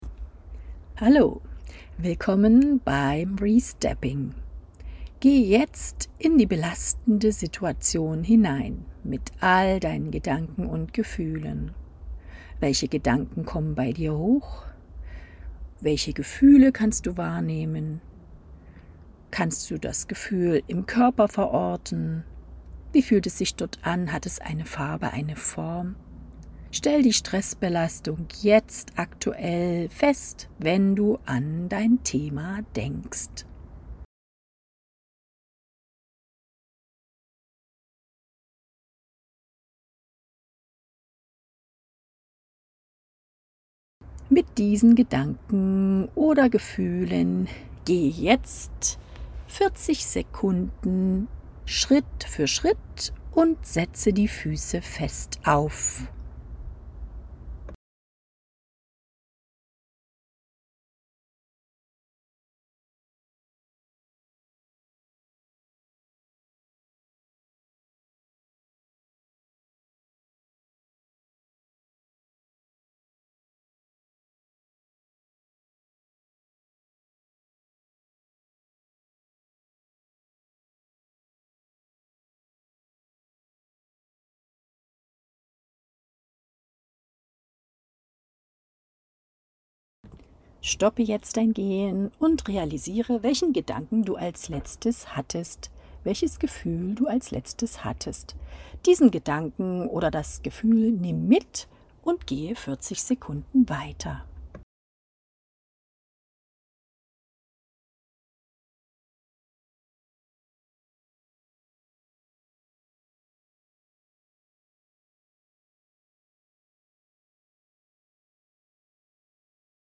Wenn Du auf den Button klickst, bekommst Du obendrein als Gimmick eine Audioanleitung, die ich für Dich eingesprochen habe! ACHTUNG – hier habe ich die Übung 15min eingsprochen, wenn Du länger benötigst, musst Du zurückspulen….